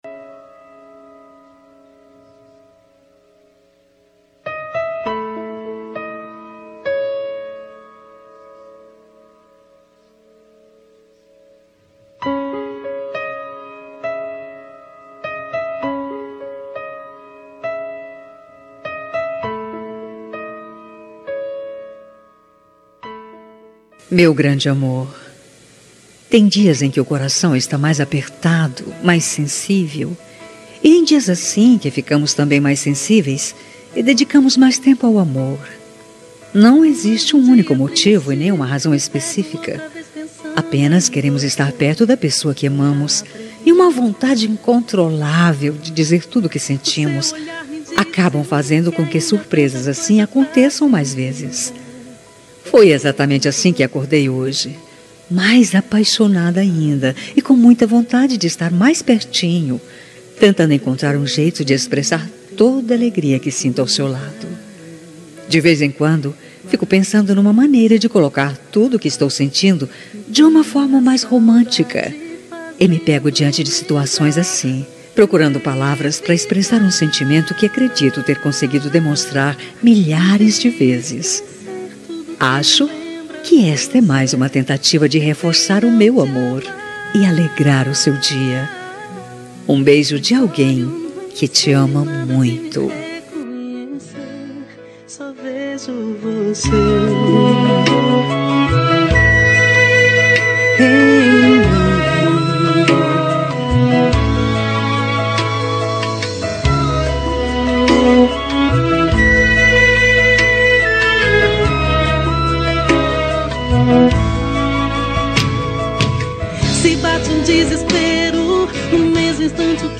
Telemensagem Romântica – Voz Feminina – Cód: 202090